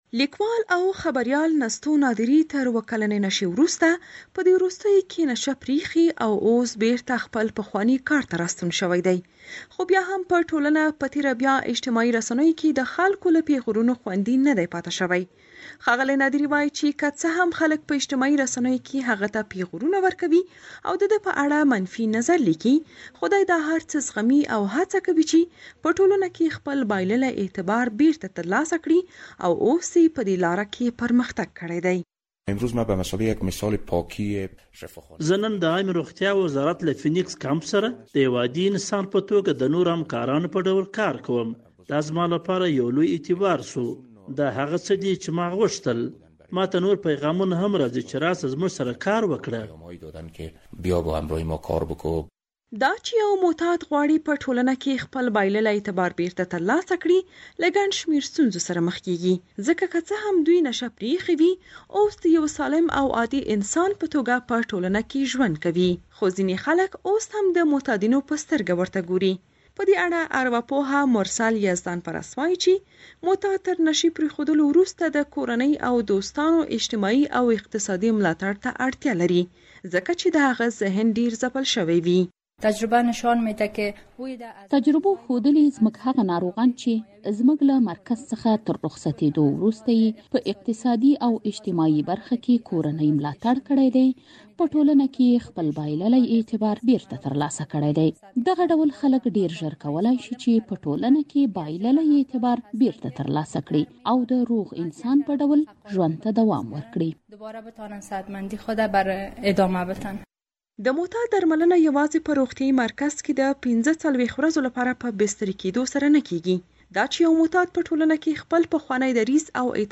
راپور- فیچر